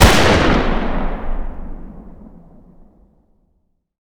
fire-dist-44mag-pistol-ext-04.ogg